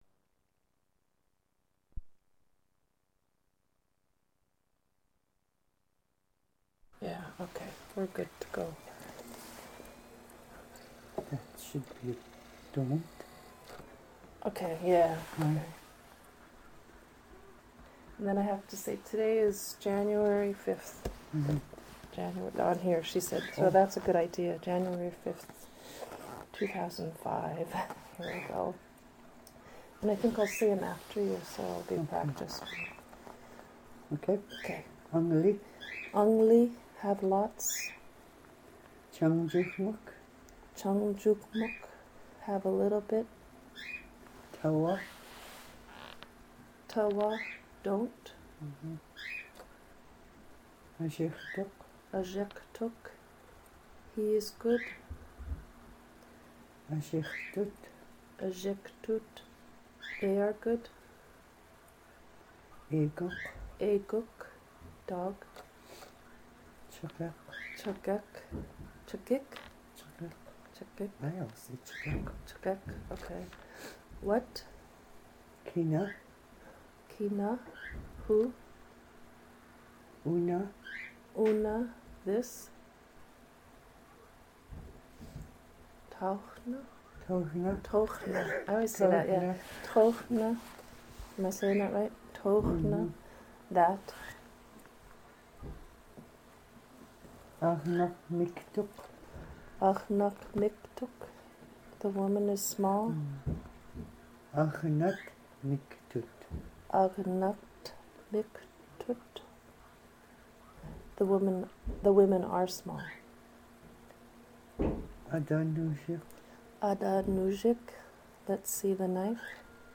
Original Format: MiniDisc (AM470:44A)
Location Description: Old Harbor, Alaska